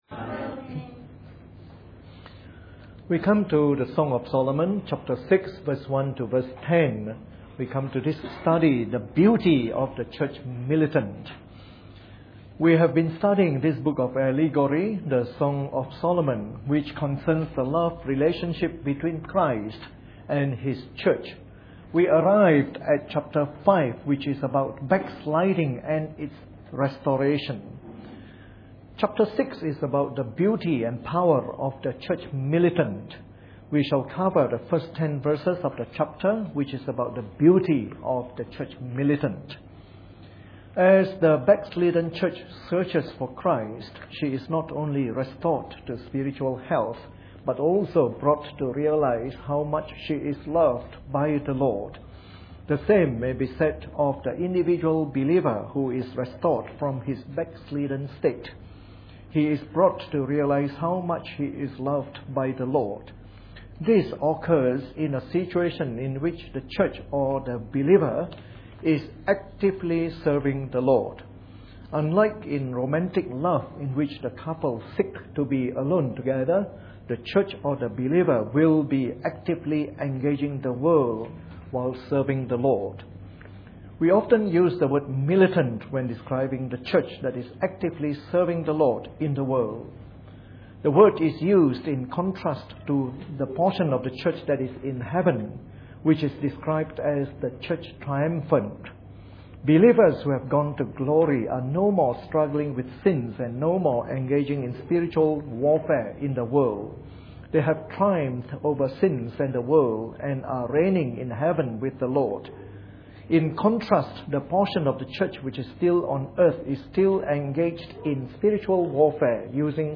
Preached on the 18th of January 2012 during the Bible Study from our new series on the Song of Solomon.